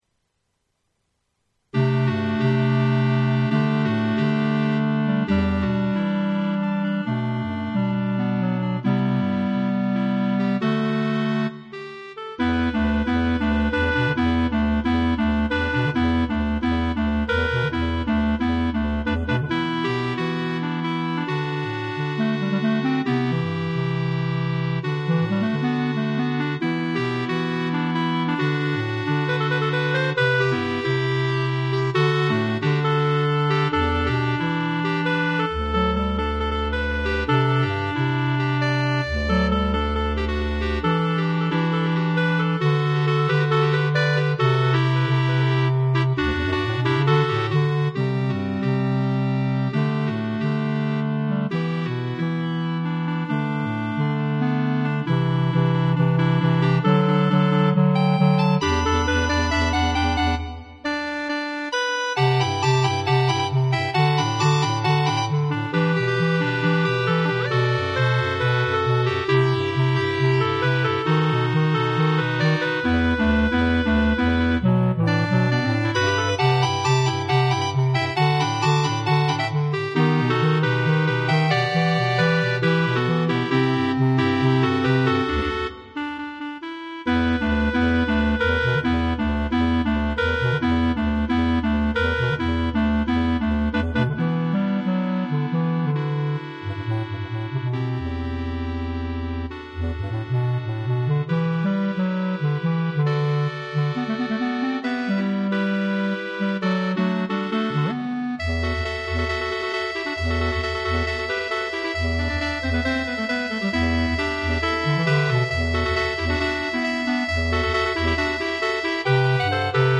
B♭ Clarinet 1 B♭ Clarinet 2 B♭ Clarinet 3 Bass Clarinet
单簧管四重奏
请在低音单簧管（bass clarinet）的节拍伴奏下，保持速度感进行演奏。这次的改编是原曲调、完整版的编排。